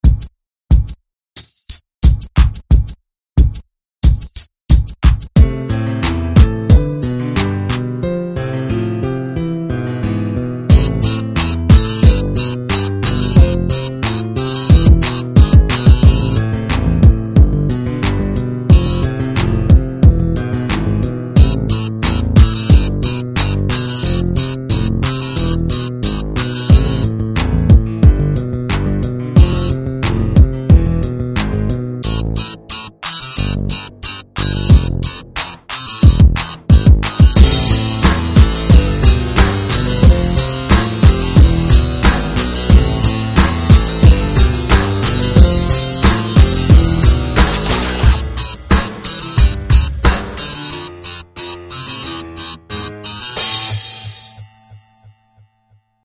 Сразу говорю - качество - говно (если здесь таковое имеется), т. к. больше 500Кб прикрепить нельзя((